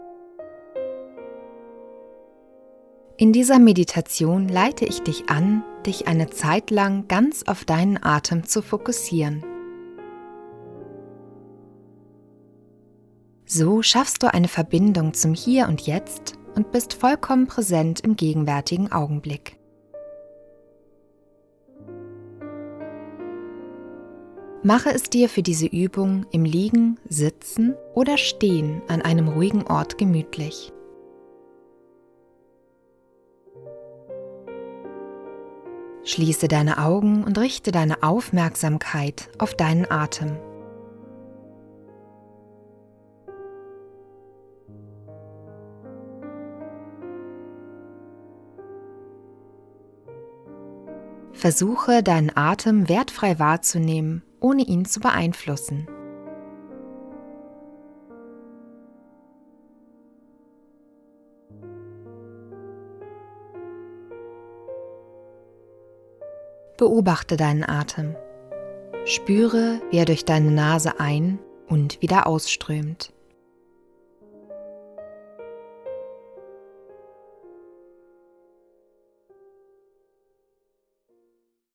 Mantrameditation_Hörprobe herunterladen